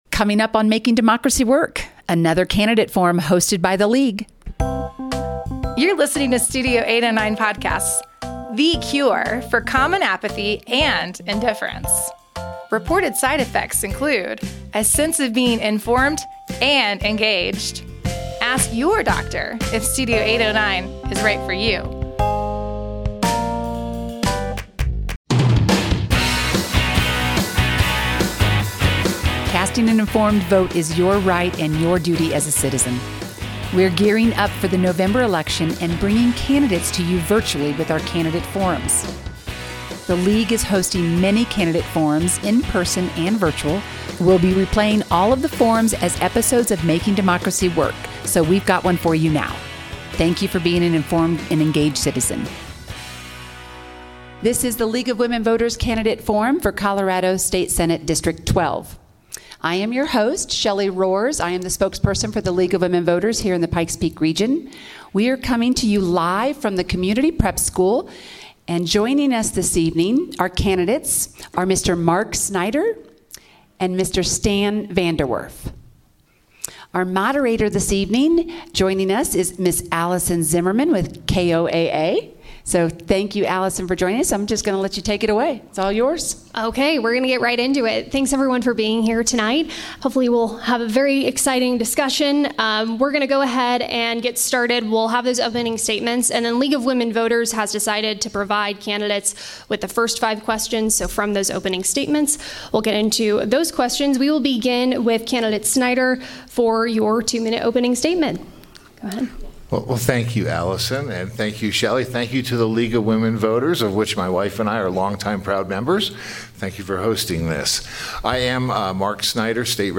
Colorado Senate District 12 Candidate Forum 2024
Candidates Marc Snyder (Democrat) and Stan VanderWerf (Republican) attended the forum.